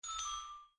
Soft-Notifications - Bell - Ding-Dong
Bell ding Notification SFX Soft UI sound effect free sound royalty free Sound Effects